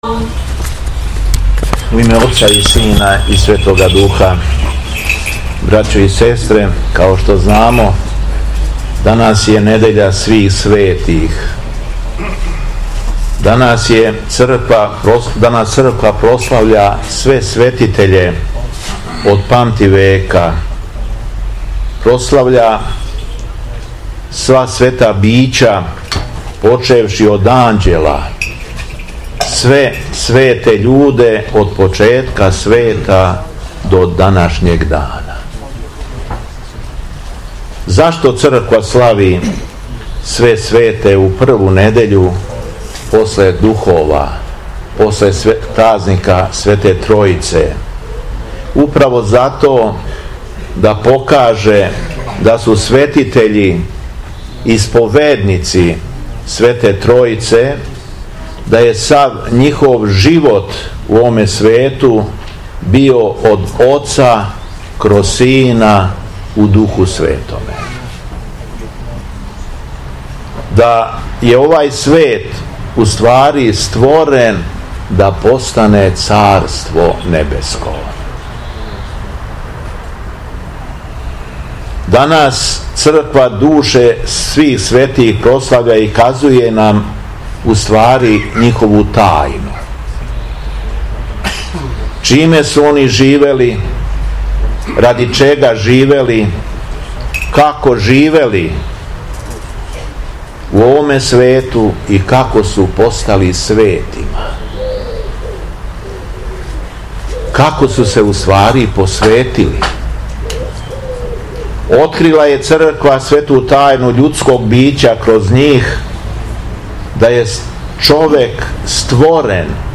Са благословом Његовог Преосвештенства Епископа шумадијског Господина Јована, у Недељу свих светих, 11. јуна 2023. године, у Старој Милошевој Цркви у Крагујевцу је одржан Фестивал хорова Епархије шумадијске, који се ове године одржава двадест трећи пут.
Беседа Његовог Преосвештенства Епископа шумадијског г. Јована